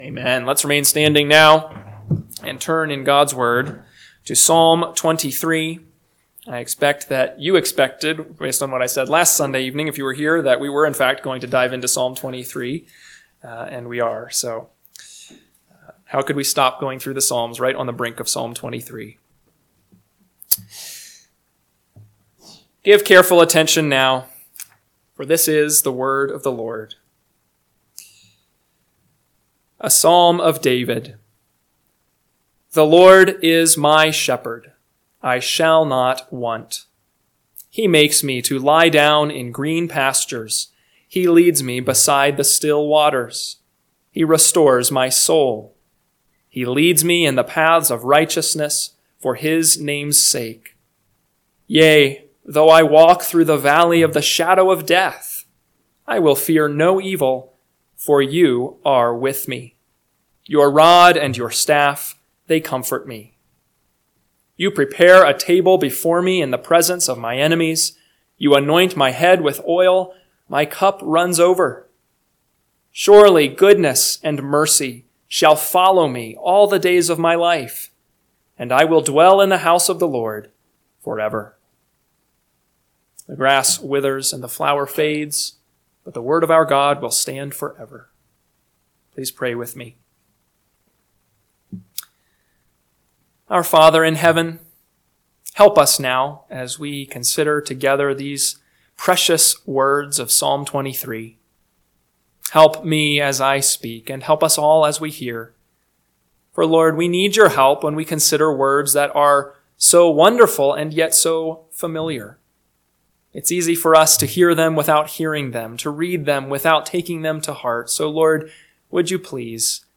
PM Sermon – 2/9/2025 – Psalm 23 – Northwoods Sermons